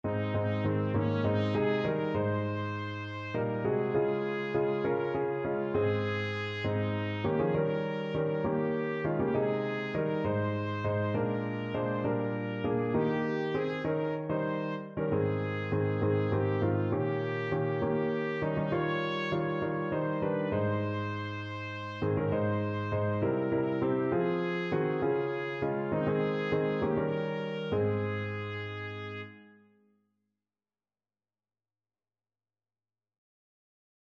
Trumpet
"In the Garden" (sometimes rendered by its first line "I Come to the Garden Alone") is a gospel song written by American songwriter C. Austin Miles
Ab major (Sounding Pitch) Bb major (Trumpet in Bb) (View more Ab major Music for Trumpet )
6/8 (View more 6/8 Music)
C5-Db6
Classical (View more Classical Trumpet Music)